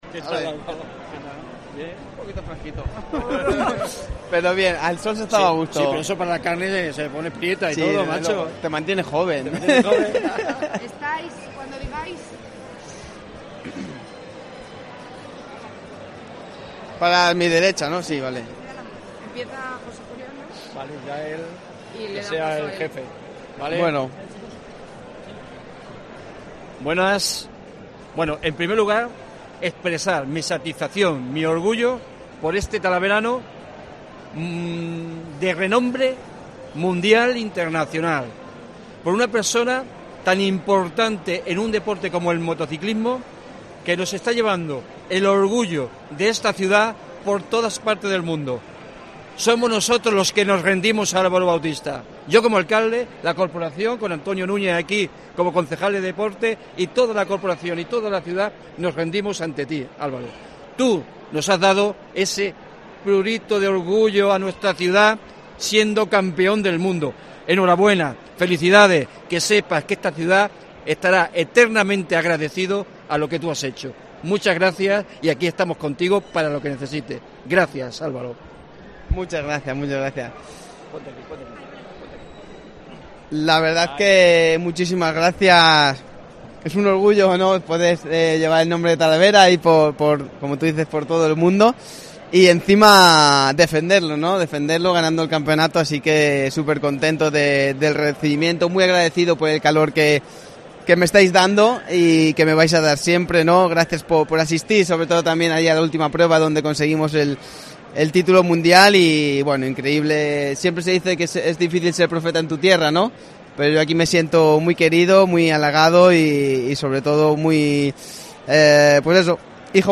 Entrevista Álvaro Bautista en el homenaje recibido en su ciudad, Talavera de la Reina